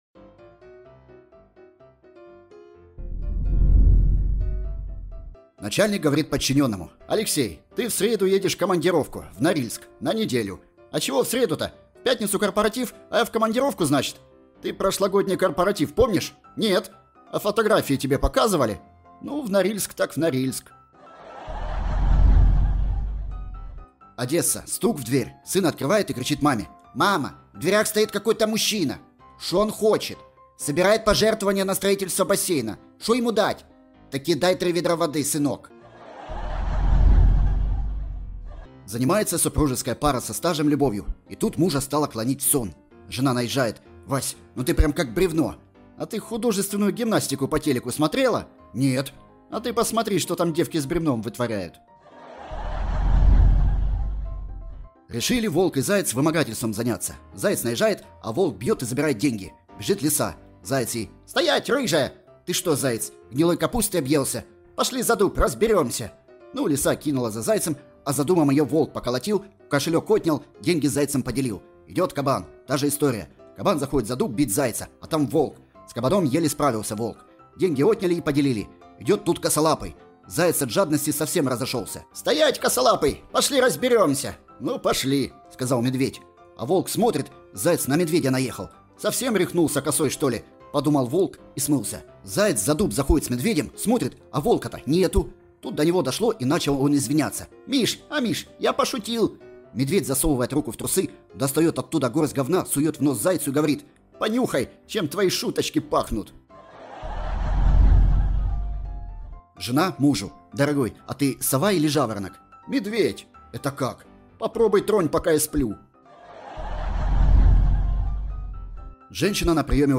Анекдот mp3